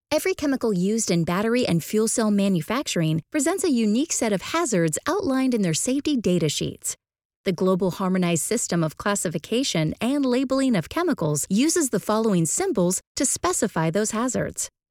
Female
Yng Adult (18-29), Adult (30-50)
Words that describe my voice are Genuine, Informative, Persuasive.
All our voice actors have professional broadcast quality recording studios.